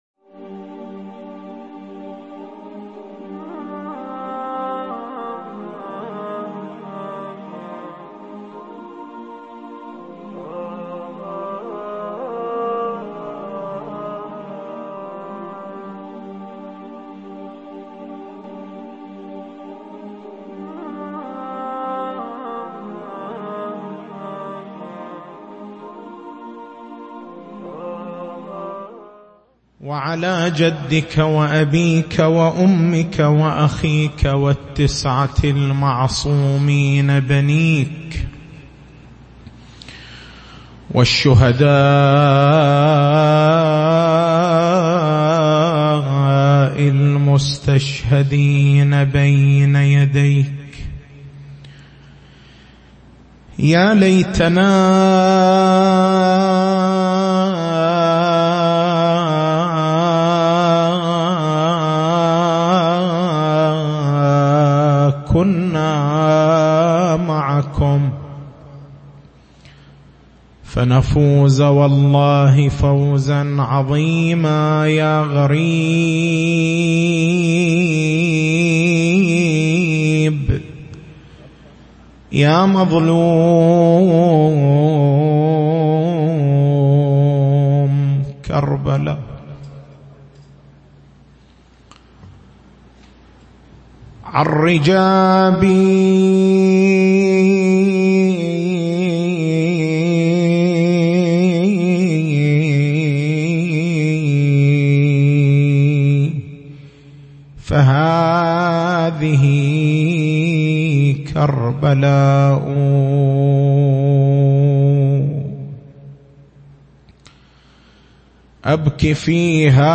تاريخ المحاضرة: 05/09/1439 نقاط البحث: هل الأسماء الإلهية محدودة؟